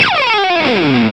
GTR MED S0BR.wav